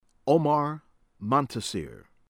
MAITIQ, AHMED AH-mehd mah-ee-TEEK